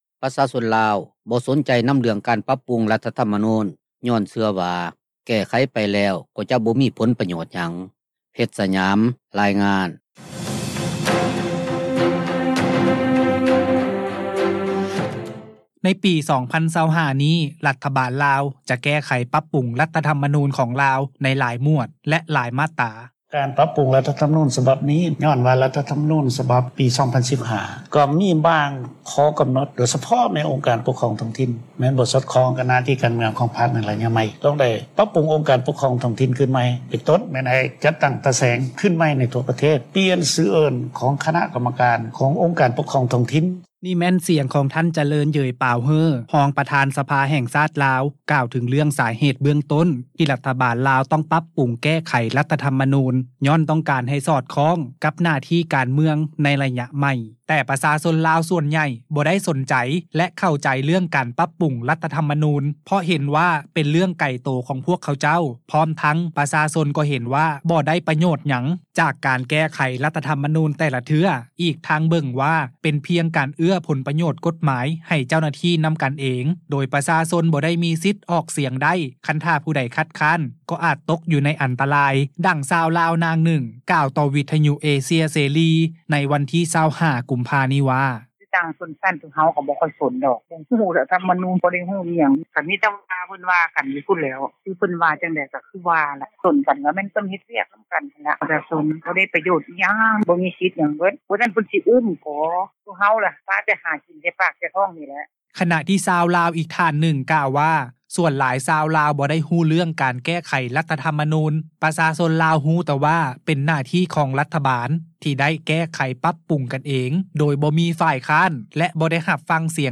ນີ້ ແມ່ນສຽງຂອງທ່ານ ຈະເລີນ ເຍຍປາວເຮີ ຮອງປະທານສະພາແຫ່ງຊາດ ກ່າວເຖິງເລື່ອງ ສາເຫດເບື້ອງຕົ້ນ ທີ່ລັດຖະບານລາວ ຕ້ອງປັບປຸງ ແກ້ໄຂ ລັດຖະທໍາມະນູນ ຍ້ອນຕ້ອງການ ໃຫ້ສອດຄ່ອງ ກັບໜ້າທີ່ການເມືອງ ໃນໄລຍະໃໝ່.